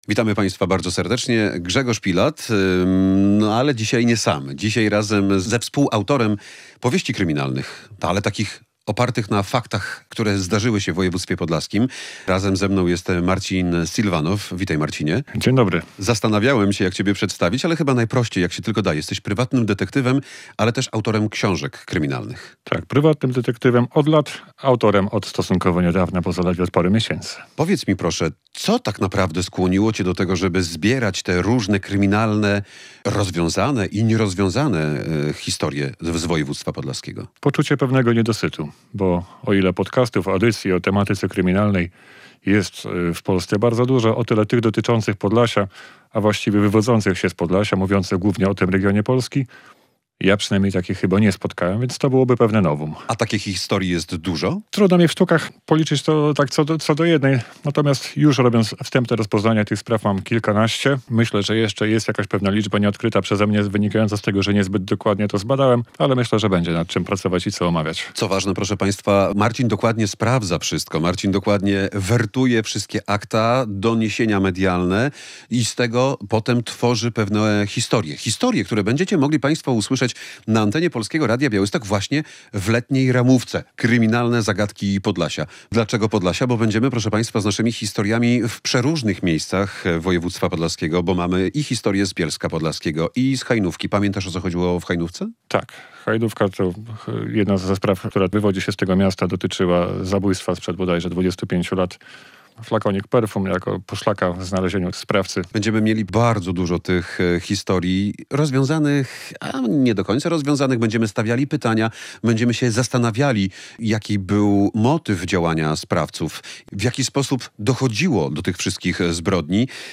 W sobotę (24.06) zapraszaliśmy do słuchania specjalnego programu Polskiego Radia Białystok, który nadawaliśmy z naszego radiowego ogrodu.